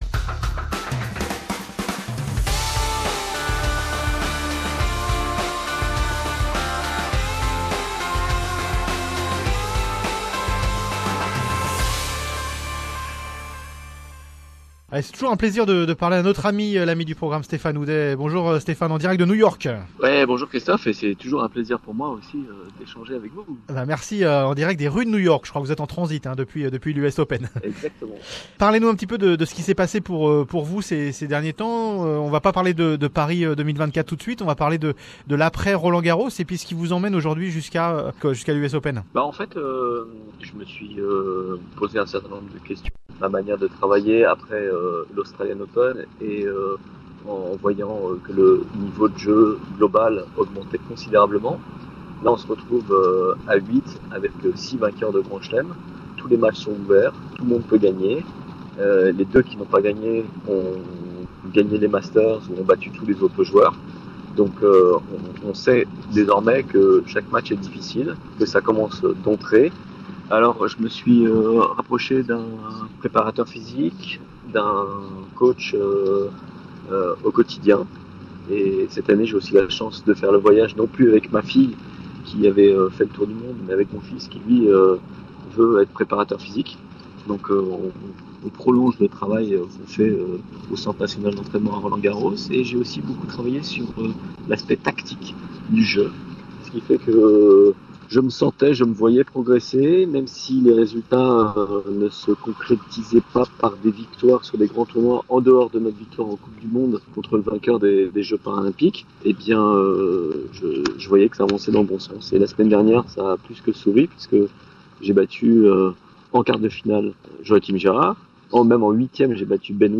Stephane Houdet est a la conquete du titre de l'USOpen en Wheelchair Tennis, on en parle avec lui depuis les rues de New York.